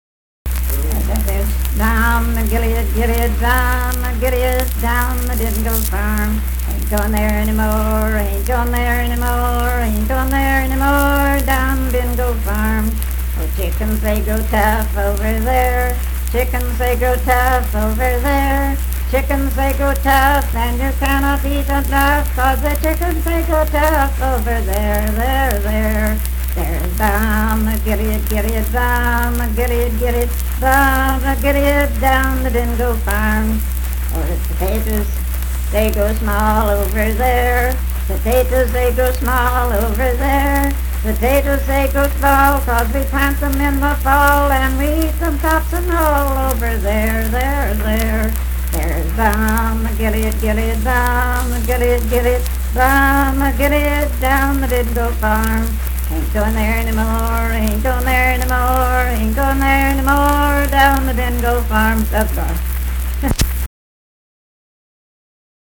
Unaccompanied vocal music performance
Dance, Game, and Party Songs
Voice (sung)
Jackson County (W. Va.)